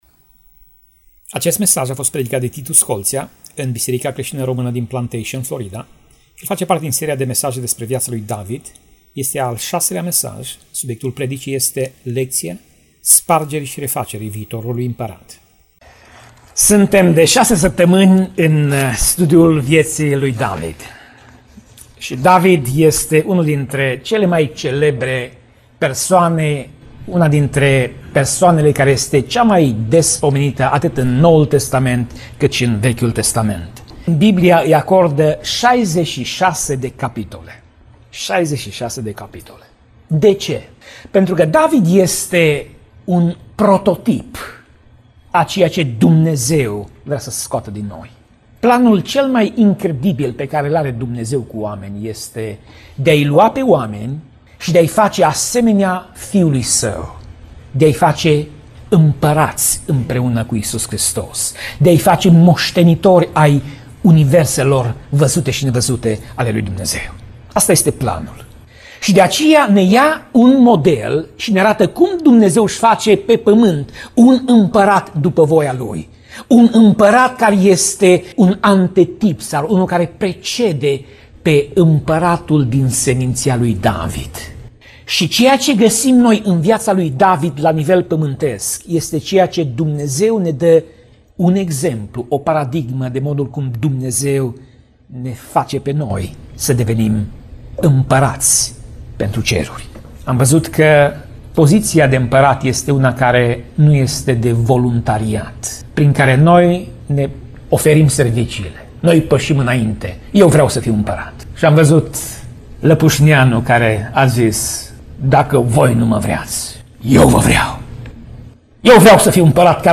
Pasaj Biblie: 1 Samuel 22:1 - 1 Samuel 22:2 Tip Mesaj: Predica